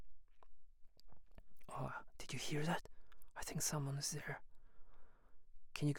emotional-speech